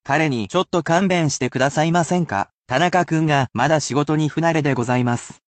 (polite speech)